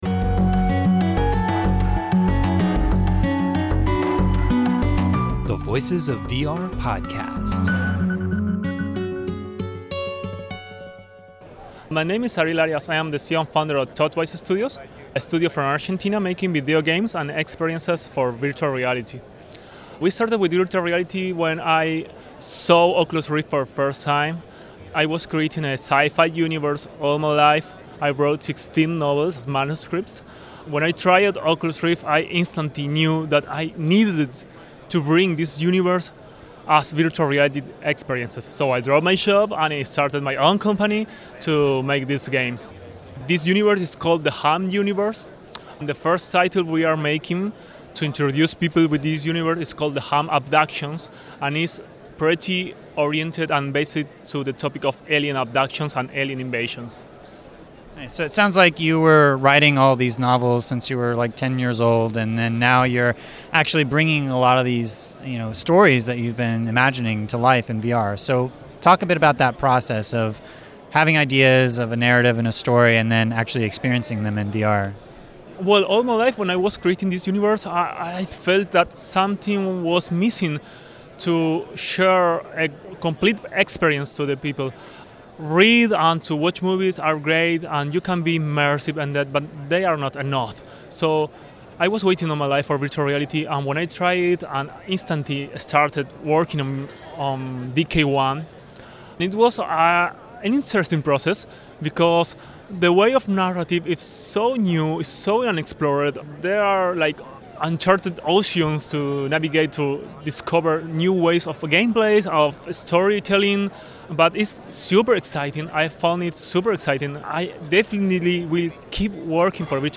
Theme music: “Fatality” by Tigoolio